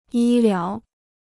医疗 (yī liáo) Free Chinese Dictionary